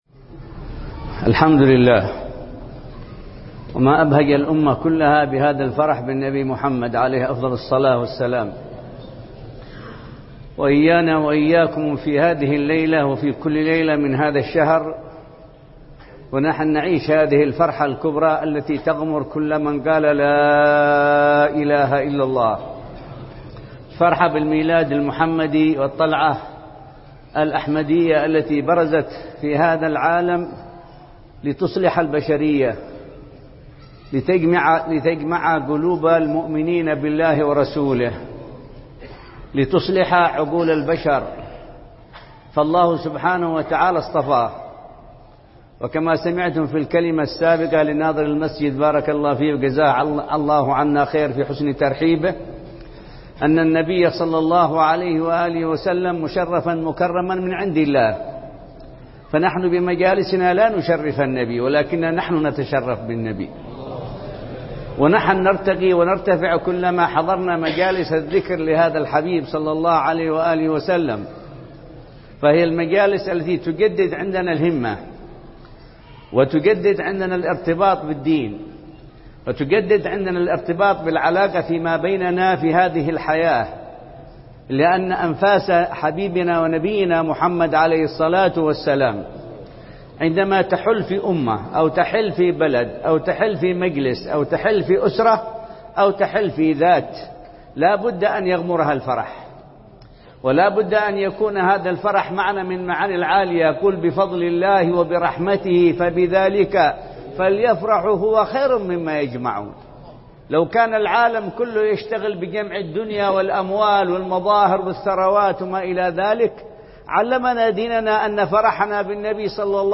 24 ربيع الأول 1438هـ جامع سيئون – حضرموت
محاضرة